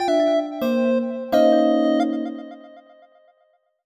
jingle_chime_25_negative.wav